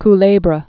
(k-lābrə)